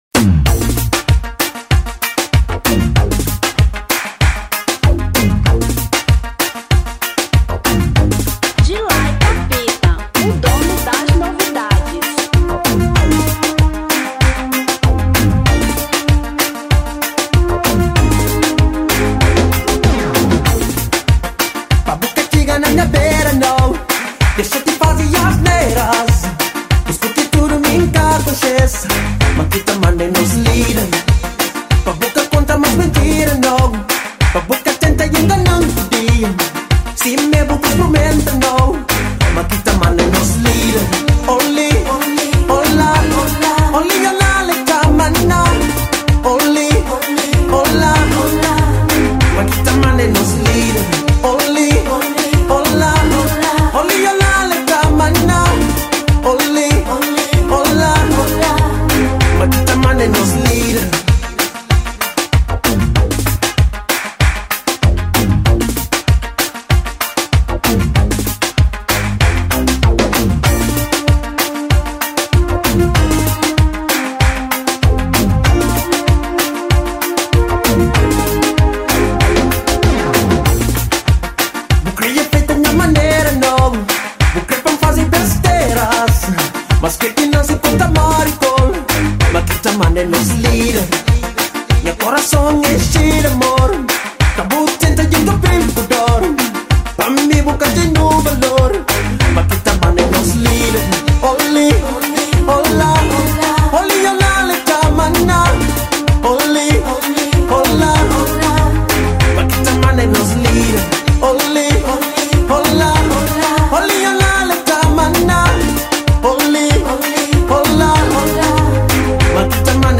Kizomba 1999